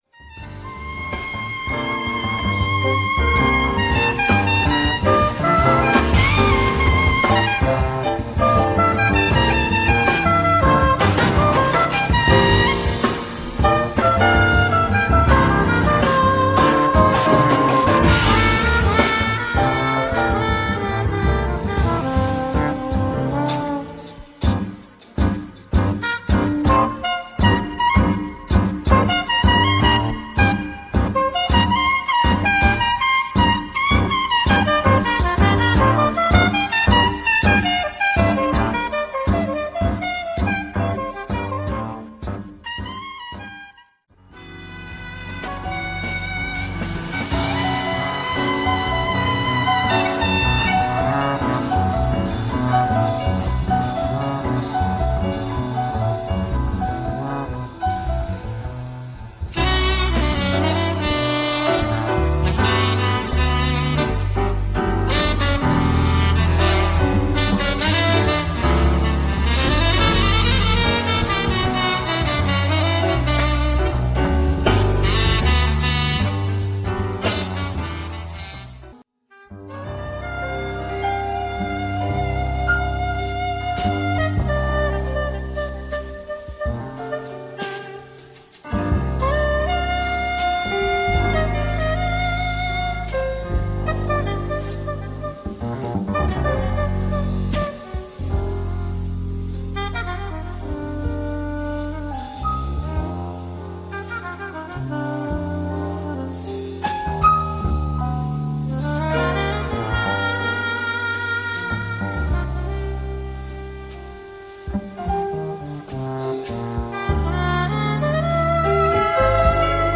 Zostrih niekoľkých skladieb vo formáte
jazzový koncert
špičkového poľského saxofonistu